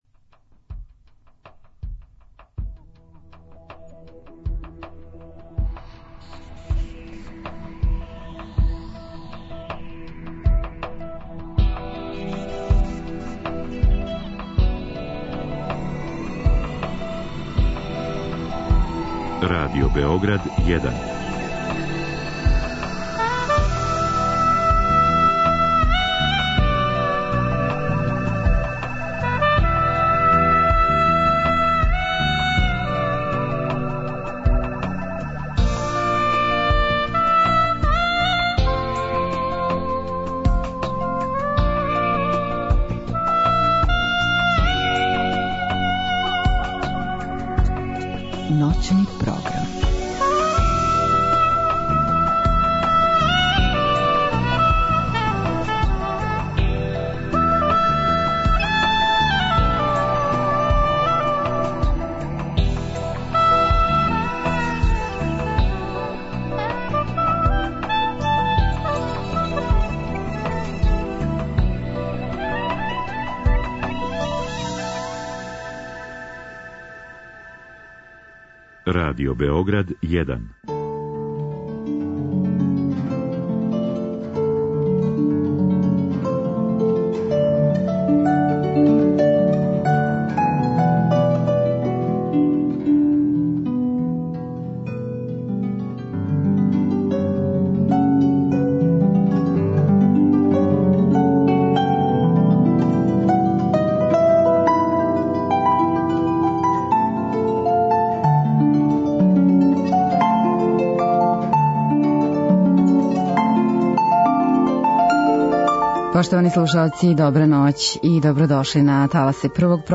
У наставку програма слусамо композиције Малера, Штрауса, Шенга и Монтевердија.